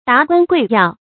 达官贵要 dá guān guì yào
达官贵要发音
成语注音 ㄉㄚˊ ㄍㄨㄢ ㄍㄨㄟˋ ㄧㄠˋ